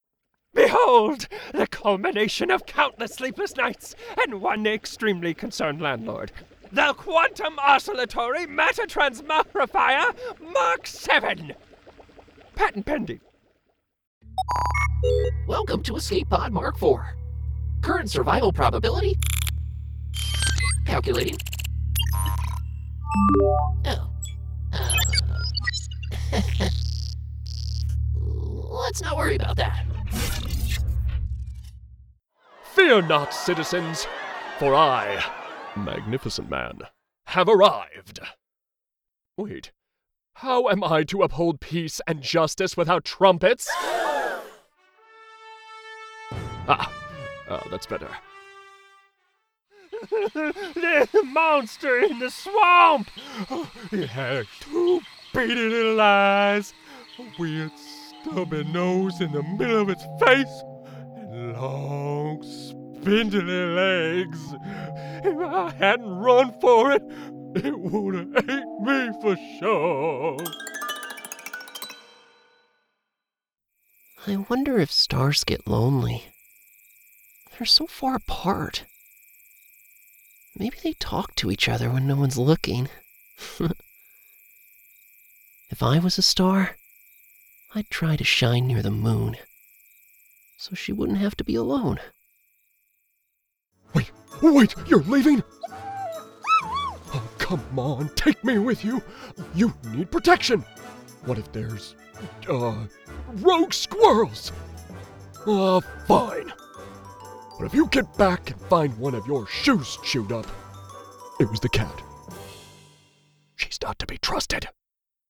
English - Midwestern U.S. English
Midwest, Neutral
Young Adult
Middle Aged
Character Voice